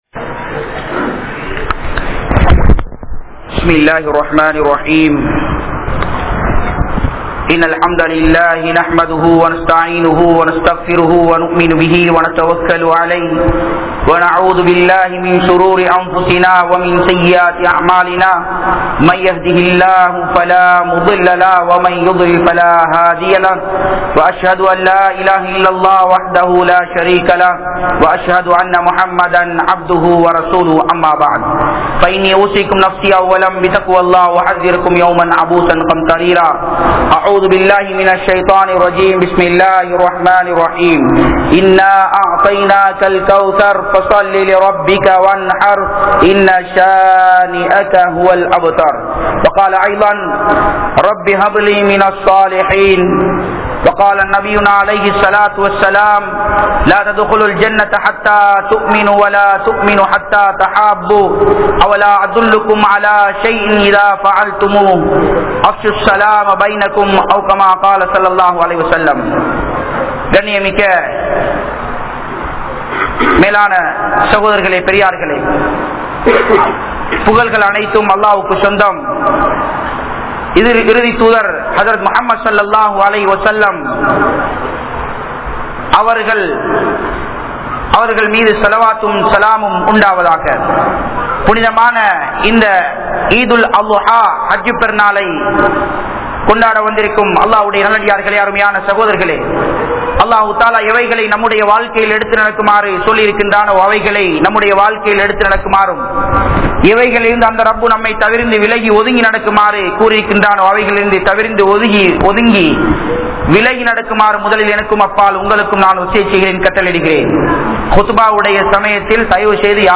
Eid Ul Alha Bayan | Audio Bayans | All Ceylon Muslim Youth Community | Addalaichenai